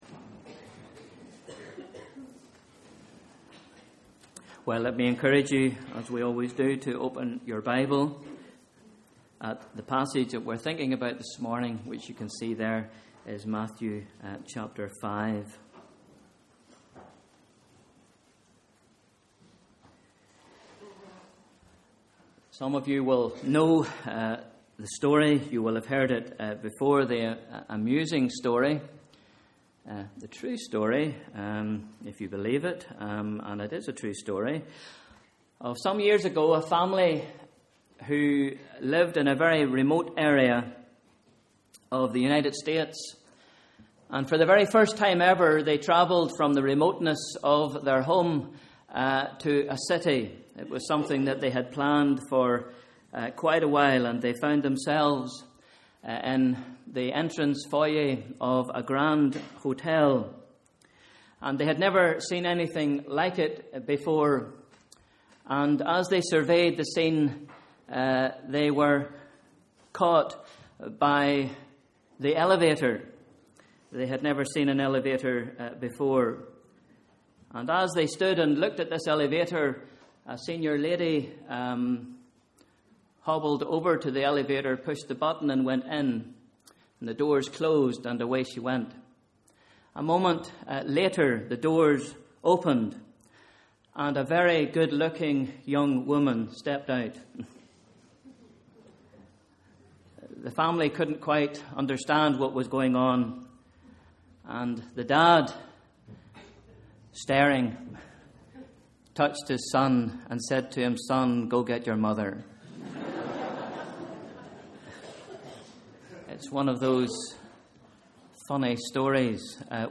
Bible Reading: Matthew 5 v 1 – 16 Sunday 13th October: Morning Service